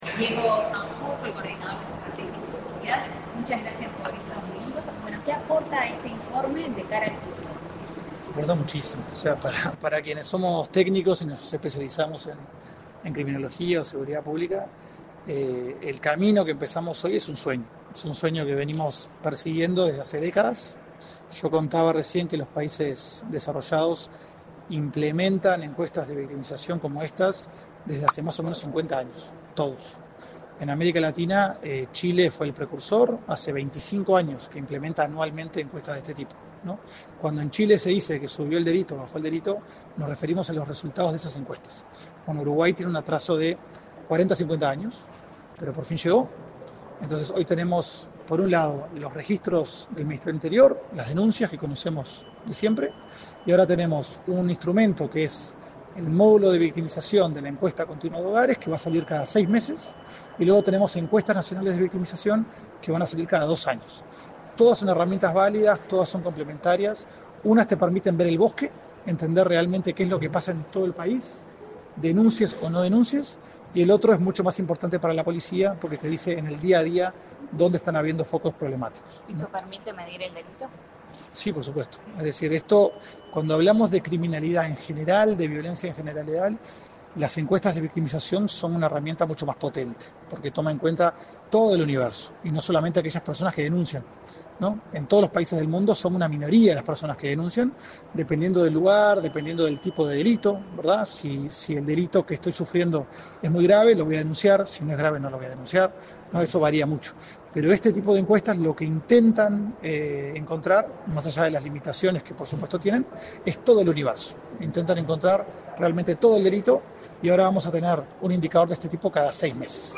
Declaraciones a la prensa del coordinador de Estrategias de Seguridad Integral y Preventiva, Diego Sanjurjo
Este 13 de febrero, el Instituto Nacional de Estadística presentó el primer informe semestral de estadísticas de victimización. Tras el evento, el coordinador de Estrategias de Seguridad Integral y Preventiva del Ministerio del Interior, Diego Sanjurjo, realizó declaraciones a la prensa.